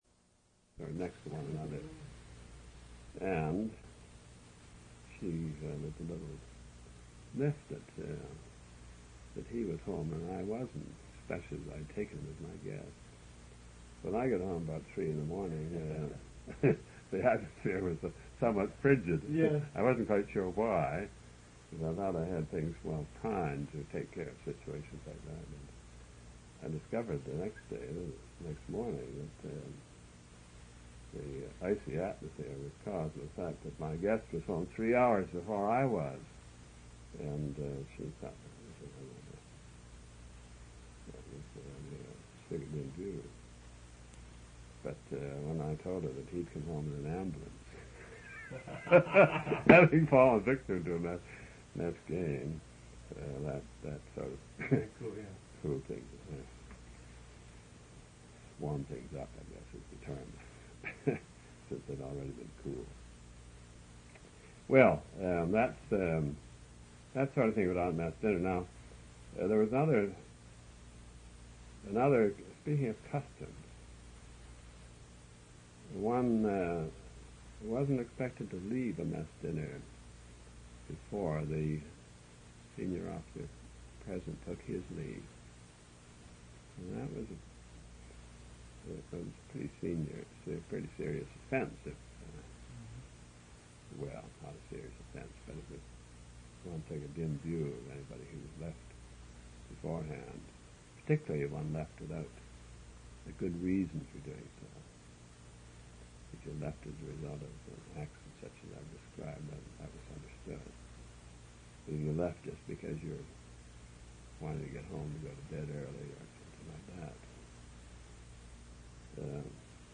An interview/narrative of Clarence R. Dunlap's experiences during World War II.